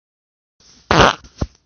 真实的屁 " 屁12
描述：真屁
Tag: 现实 放屁 真正